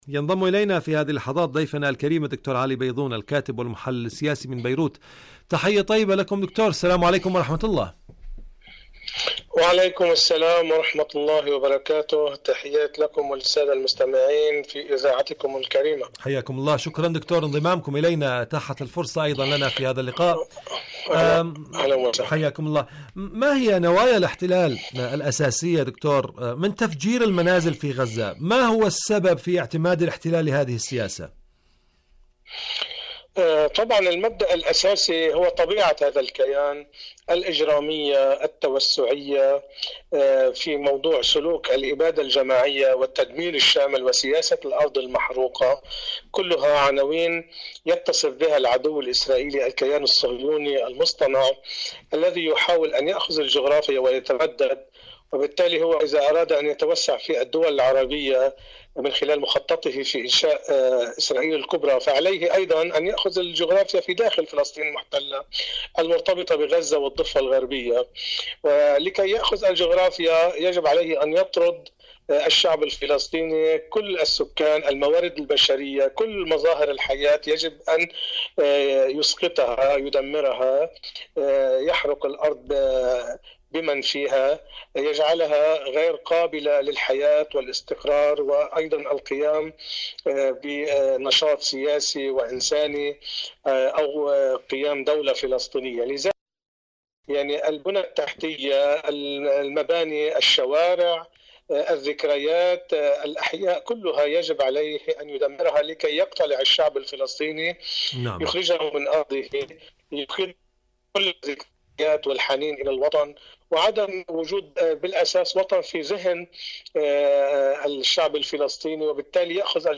برنامج حدث وحوار مقابلات إذاعية